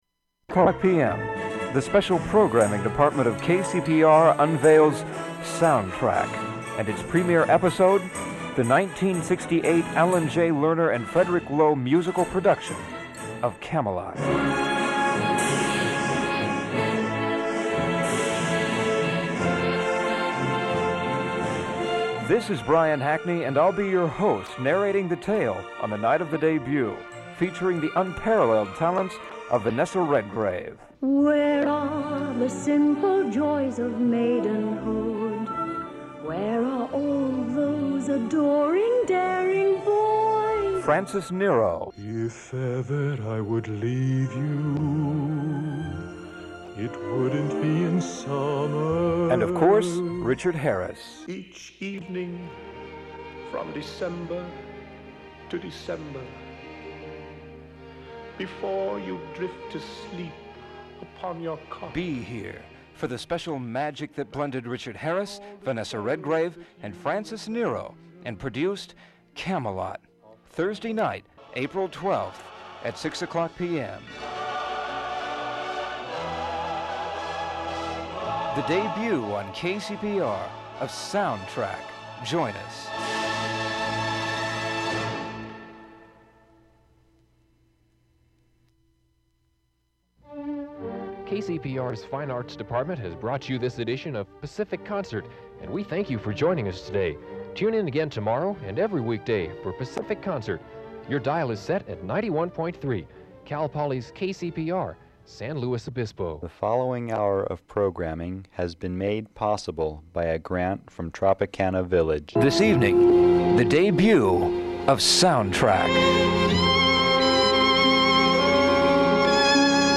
Due to the condition of the tape, the last minute of the recording could not be successfully digitized.
Open reel audiotape